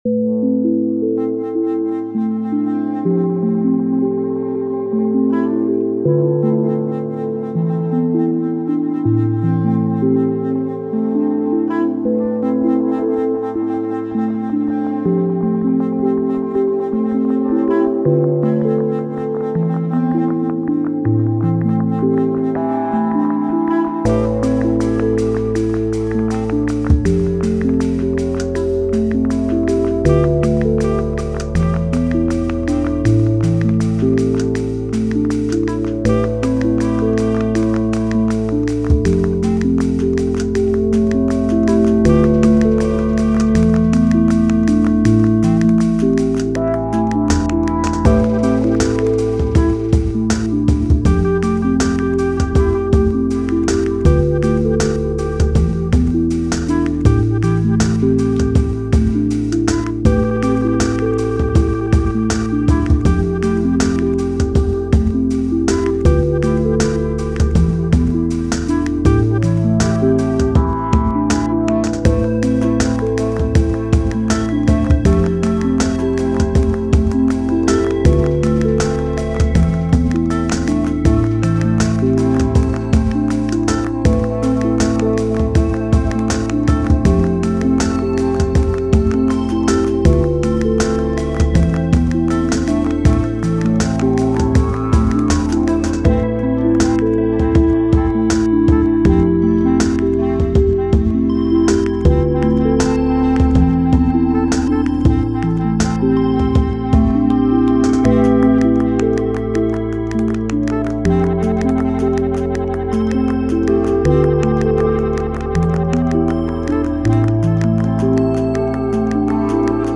Agradecimientos de fondo musical a